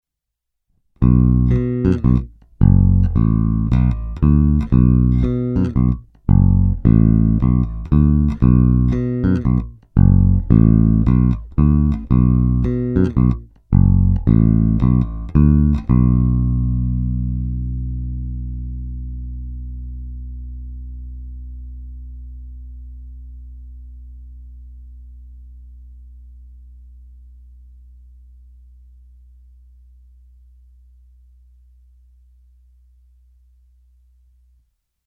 Není-li uvedeno jinak, následující nahrávky jsou vyvedeny rovnou do zvukové karty a vždy s plně otevřenou tónovou clonou a s korekcemi v nulové poloze, následně jsou jen normalizovány, jinak ponechány bez úprav.
Oba snímače